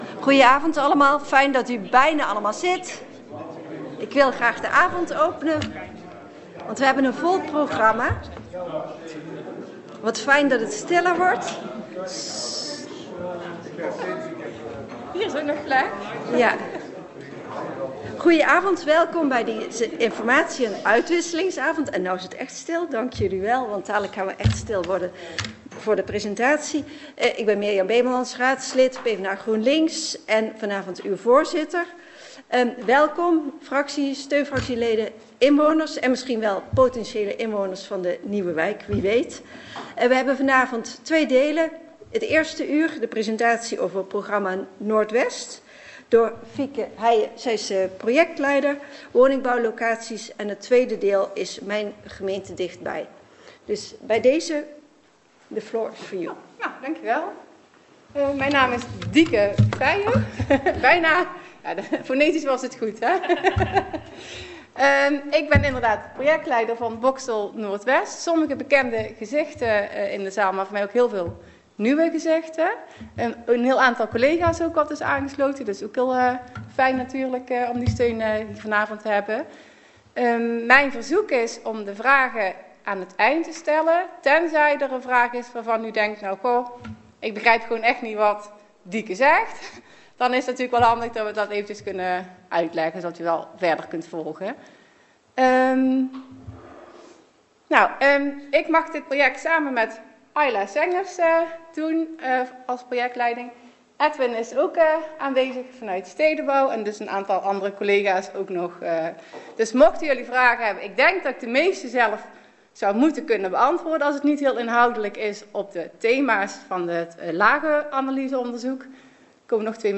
Locatie Raadzaal Boxtel
Geluidsopname presentatie praatprent Boxtel Noord-west